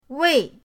wei4.mp3